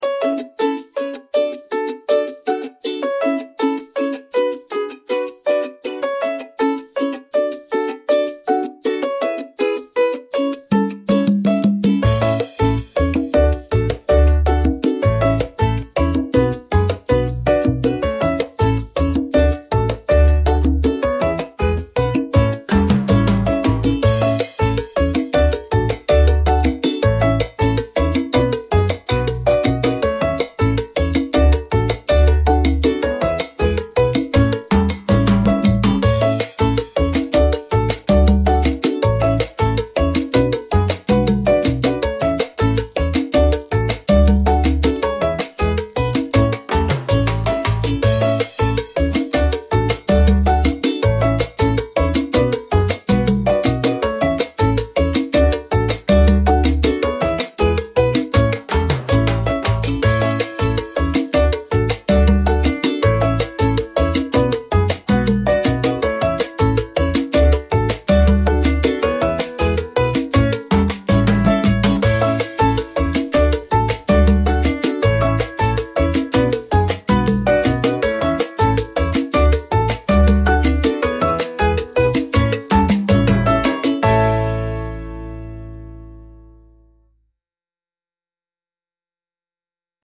calypso kids music with bongos, steel pan and cheerful singing melody